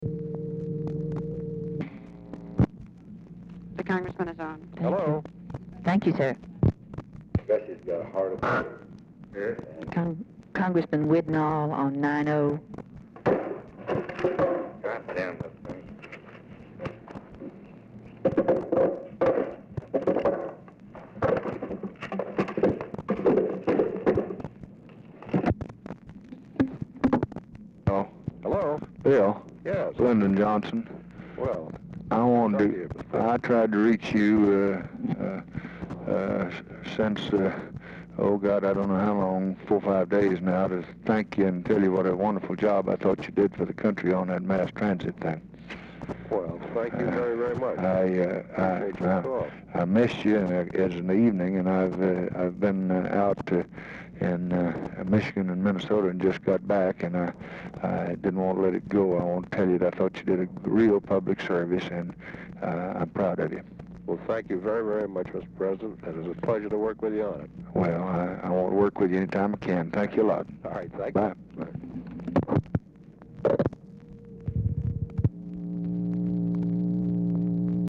Telephone conversation
Format Dictation belt
Oval Office or unknown location